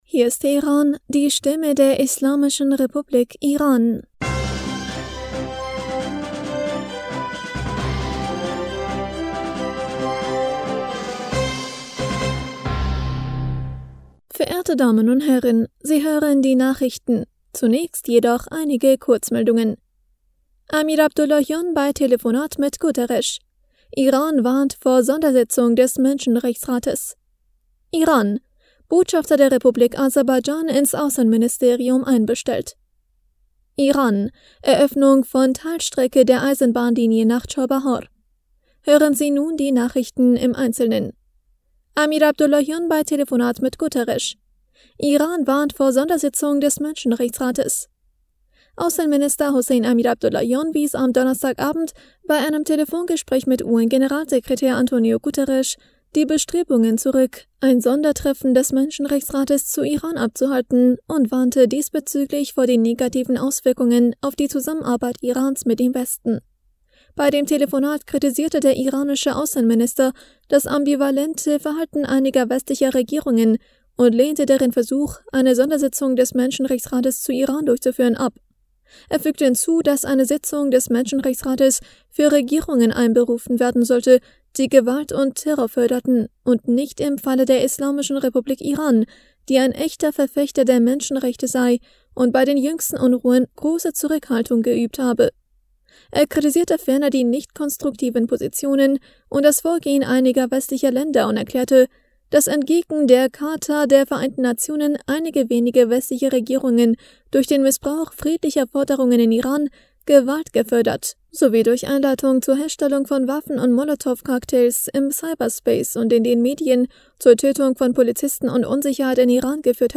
Die Nachrichten von Freitag, dem 11. November 2022